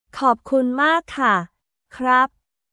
コープクン・マーク・カ／クラップ